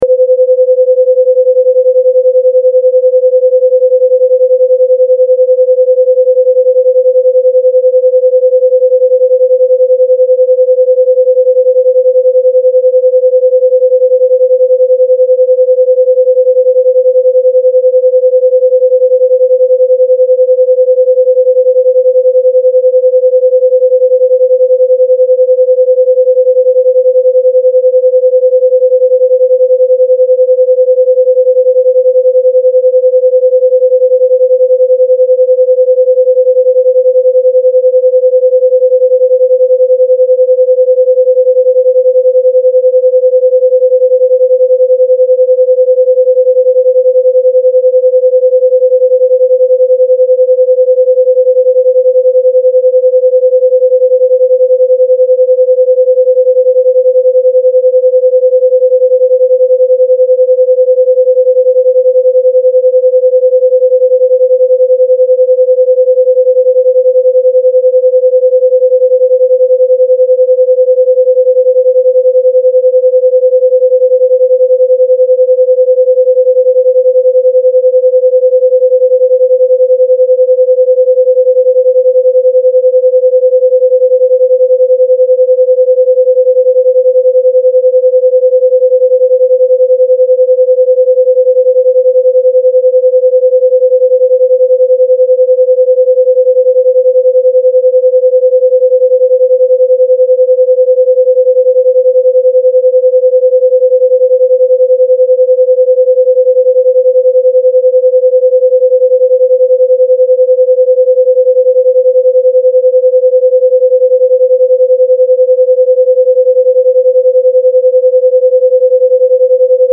Wie Alpha 10, nur unterstützt durch Rosa Rauschen (fördert bei manchen das Abschalten gegenüber Außenreizen)